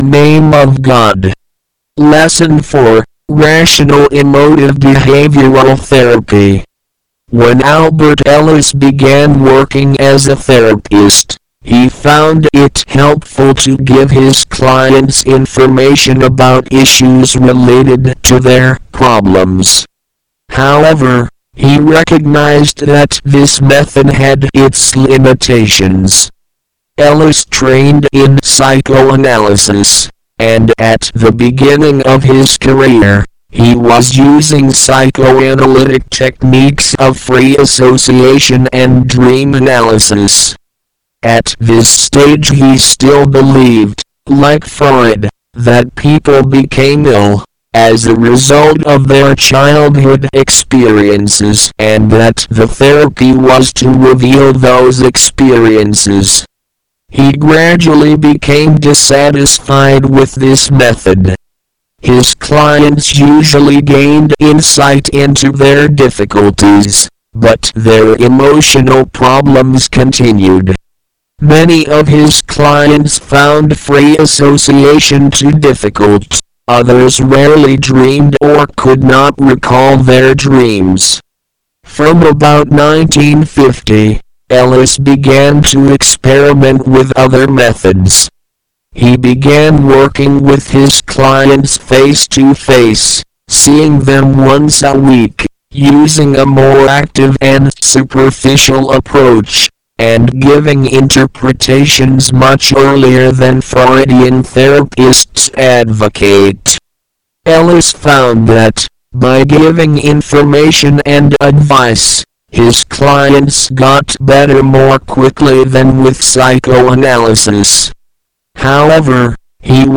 English Texts for G. Counseling-Lesson 4-Reading Once.mp3